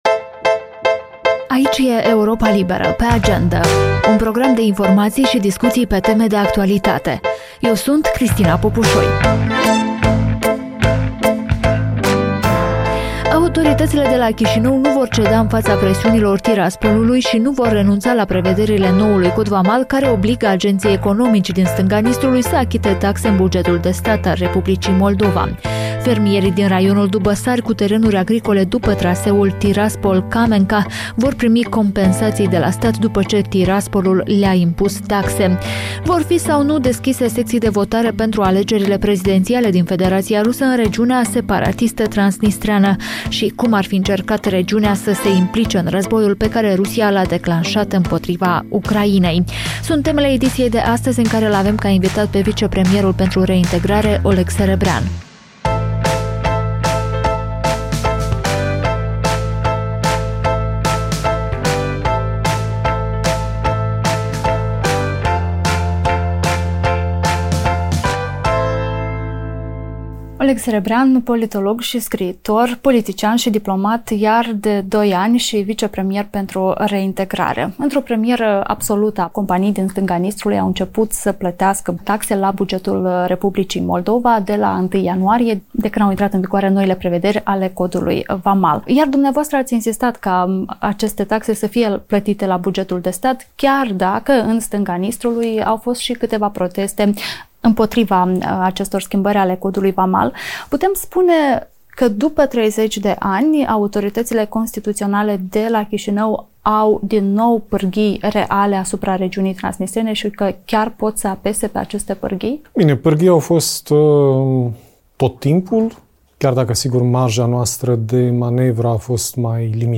Vicepremierul pentru Reintegrare Oleg Serebrian vorbește în podcastul video „Pe Agendă” de la Europa Liberă despre cum va soluționa problema fermierilor cu terenuri după traseul Tiraspol-Camenca, și de ce Chișinăul va continua să pună în practică noul cod vamal și „nu va ceda” în fața Tiraspolului.